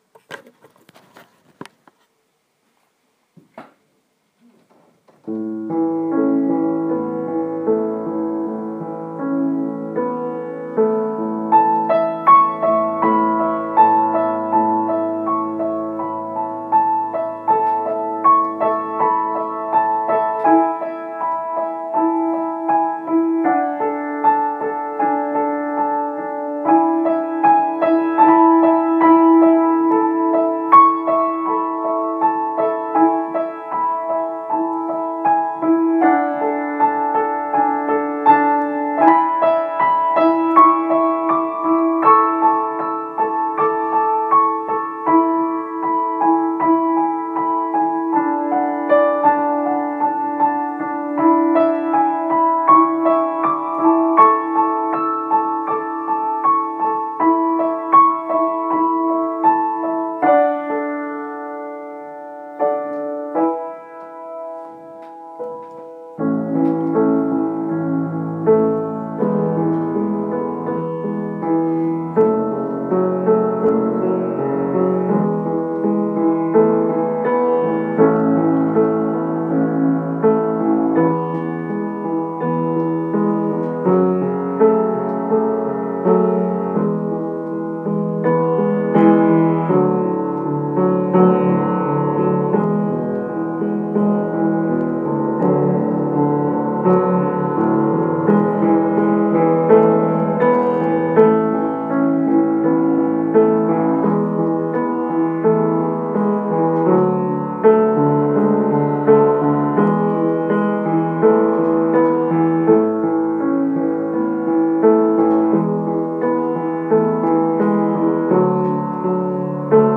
Bakgrund 5 – Akustiskt piano alternativ 1
Bakgrund-5_Akustiskt-piano-A-E-D-F_m.m4a